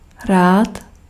Ääntäminen
Synonyymit radostný Ääntäminen : IPA: [raːt] Haettu sana löytyi näillä lähdekielillä: tšekki Käännös Konteksti Ääninäyte Adjektiivit 1. glad US Adverbit 2. like to 3. with pleasure idiomaattinen Suku: m .